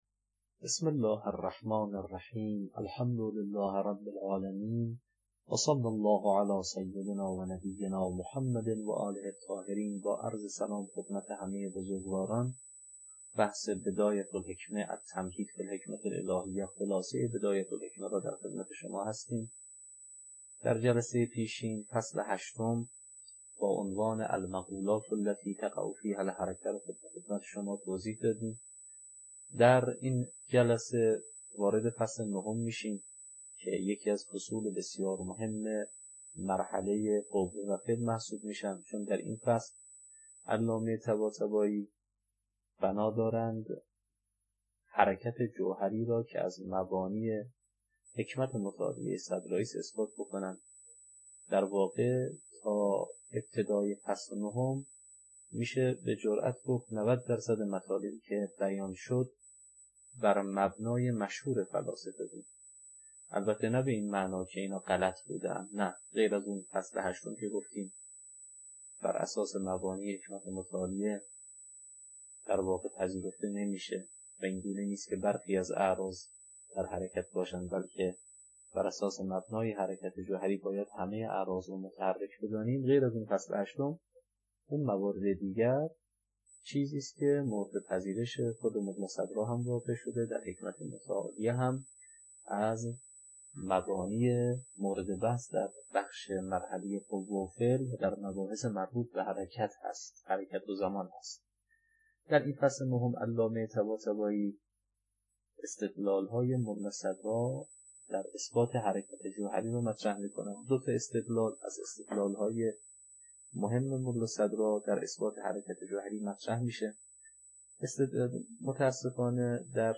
التمهيد في الحكمة الهية (خلاصه بدایه الحکمه) - تدریس